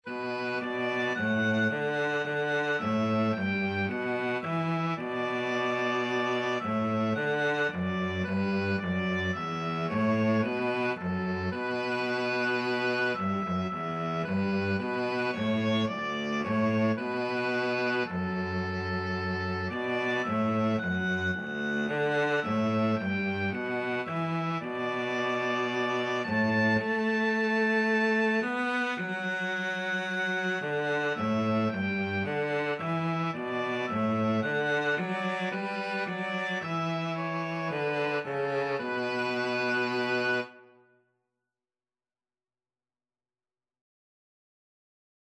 Free Sheet music for Oboe-Cello Duet
B minor (Sounding Pitch) (View more B minor Music for Oboe-Cello Duet )
Gently Flowing = c. 110
4/4 (View more 4/4 Music)
Oboe-Cello Duet  (View more Easy Oboe-Cello Duet Music)
Traditional (View more Traditional Oboe-Cello Duet Music)